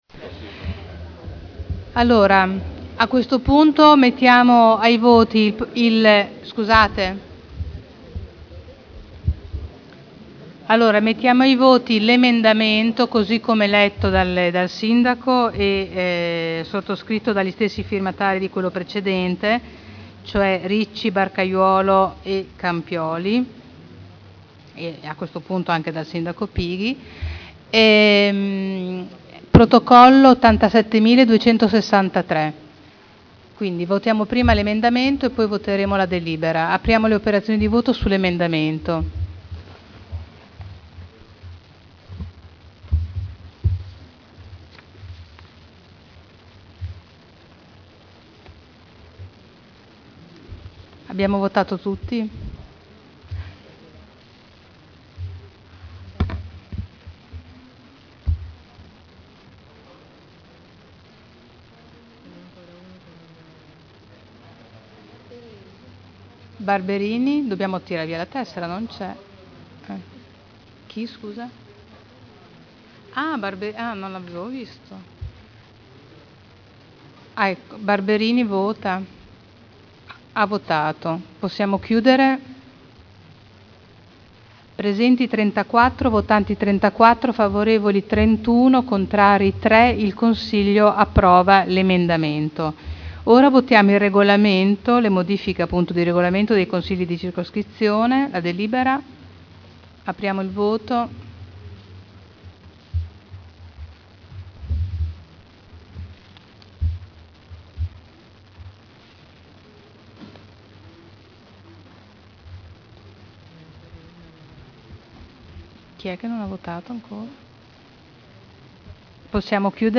Presidente — Sito Audio Consiglio Comunale
Seduta del 14/07/2011. Mette ai voti l'Emendamento presentato dal Sindaco su delibera: Regolamento dei Consigli di Circoscrizione – Modifica (Commissione Affari Istituzionali del 13 maggio 2011 e del 6 luglio 2011).